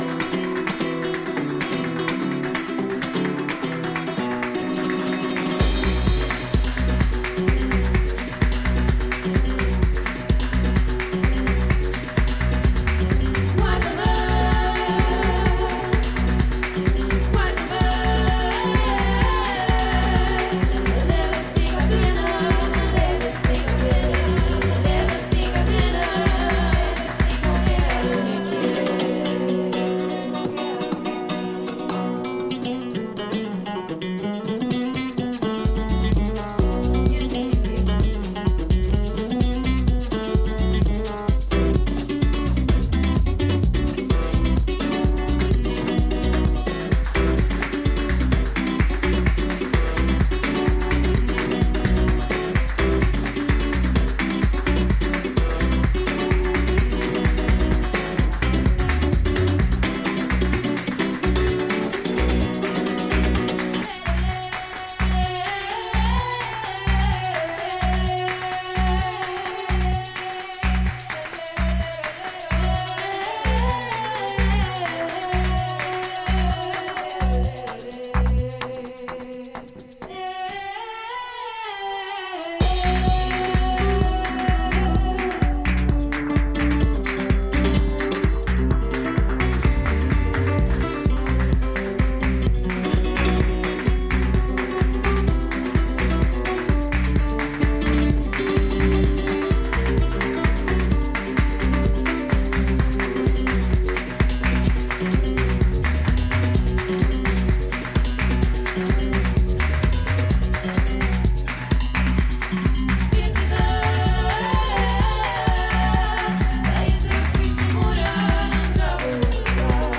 同系統のトラックにイタリア南端のイスキテッラ方言のヴォーカルが踊る